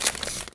R - Foley 247.wav